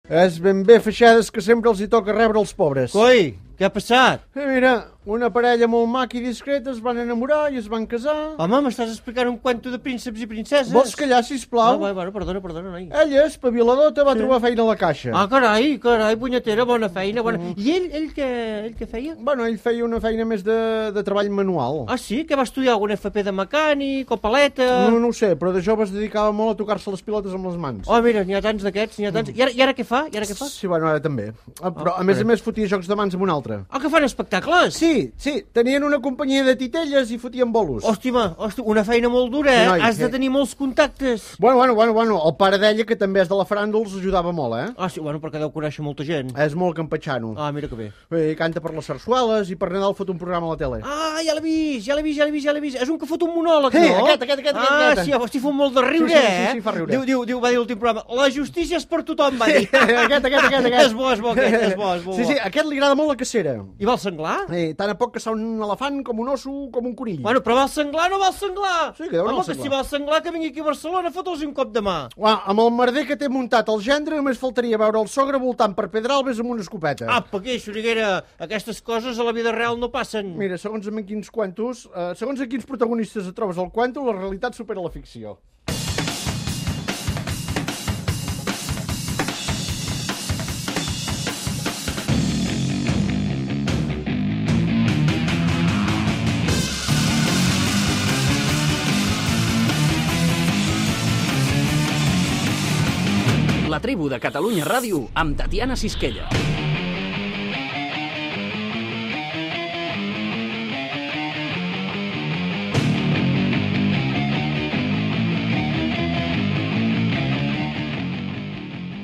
Diàleg humorístic sobre la família reial espanyola, careta del programa